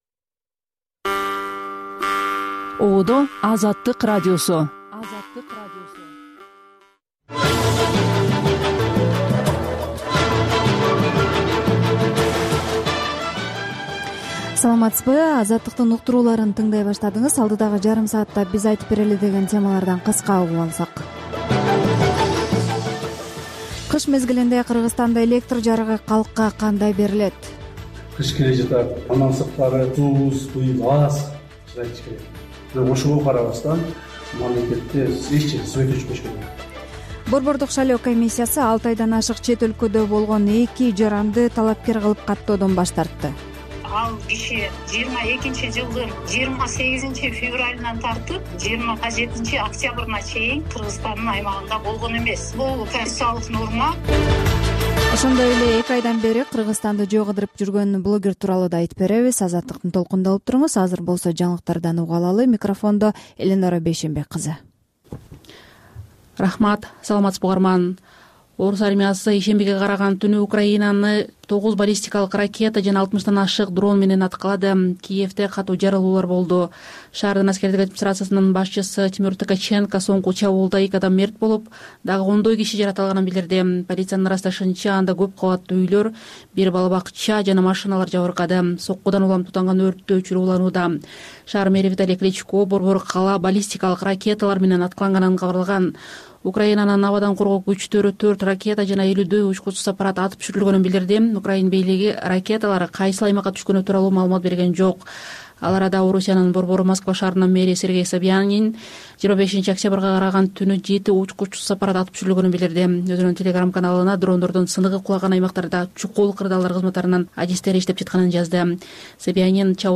Бул үналгы берүү ар күнү Бишкек убакыты боюнча саат 18:00ден 18:30га чейин обого түз чыгат.